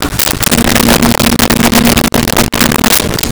E Typewriter On Off
E-Typewriter On Off.wav